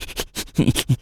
rabbit_squeak_02.wav